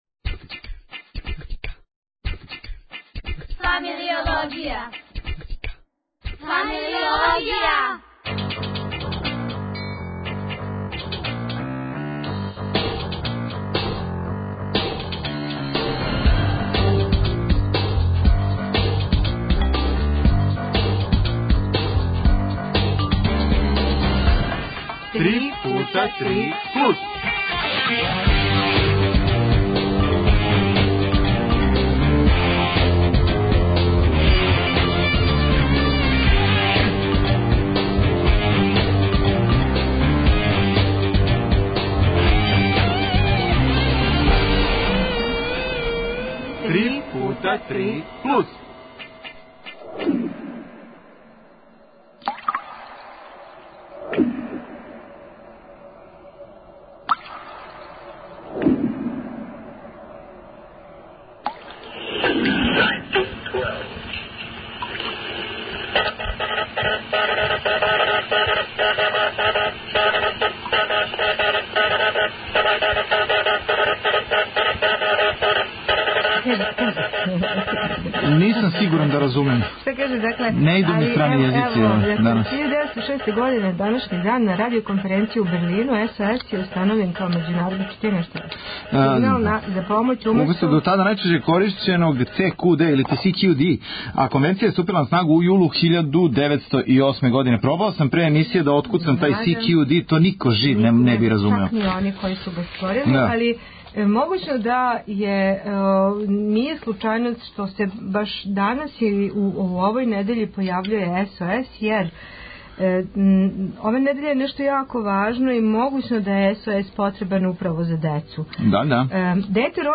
Гости су млади аутори пројекта "Balкan Dаnce Project", који долазе из разних земаља, углавном бивше Југославије.